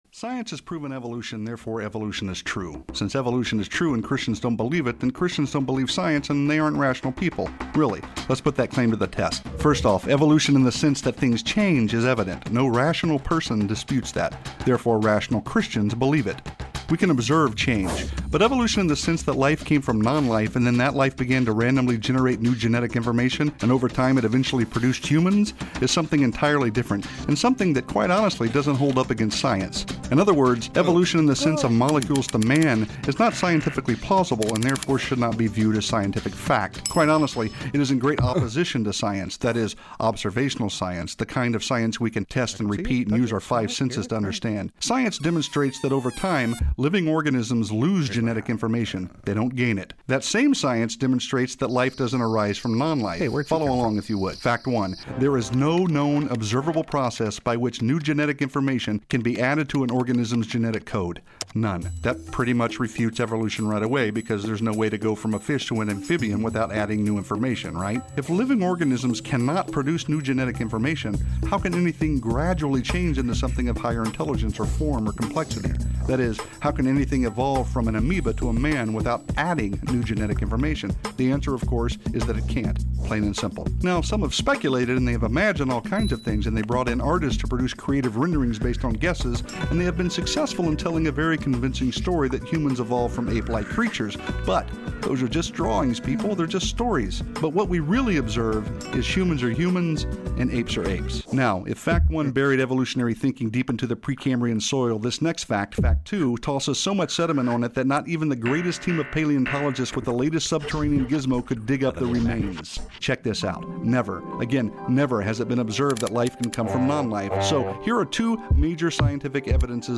In this message we will compare scientific evidence for creation to that of evolution.